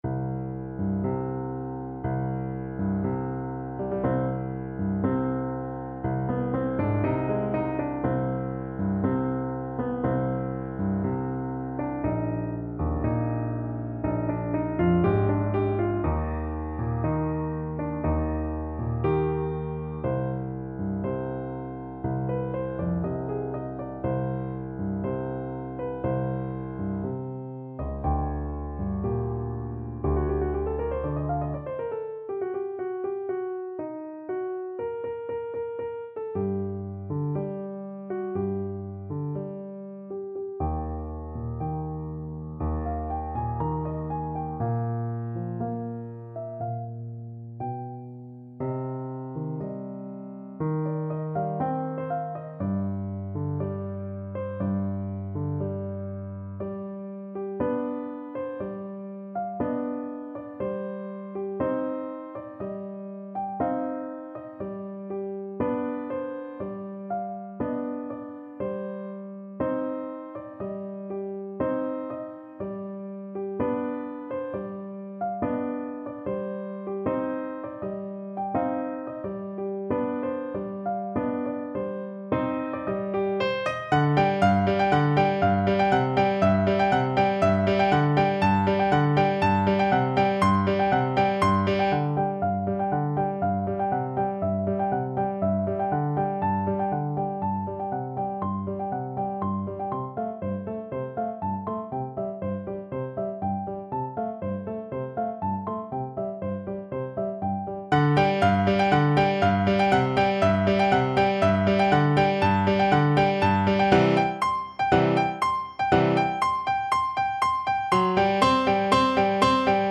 Classical Liszt, Franz Hungarian Rhapsody No. 2 Piano version
Free Sheet music for Piano
Andante mesto = 60
C minor (Sounding Pitch) (View more C minor Music for Piano )
2/4 (View more 2/4 Music)
Instrument:
Classical (View more Classical Piano Music)